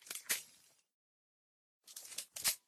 trachelium_reload.ogg